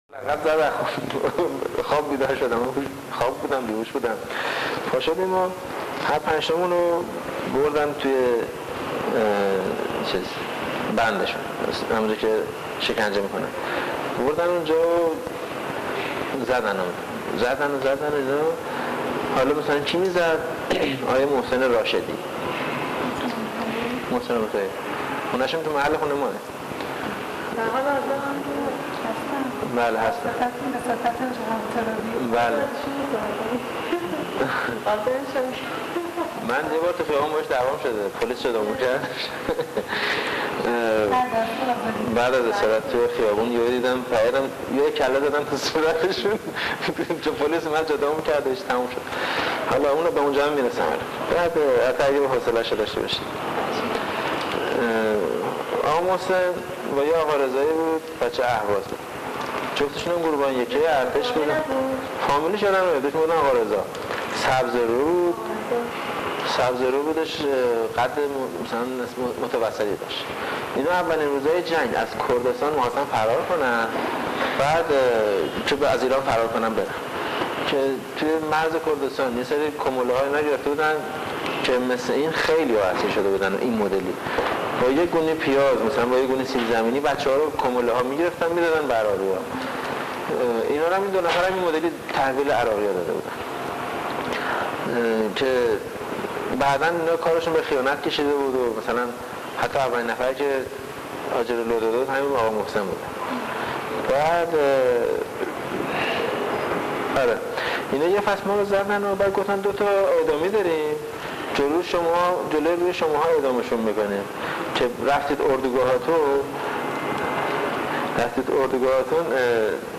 فایلهای صوتی ذیل حاصل گفت وگو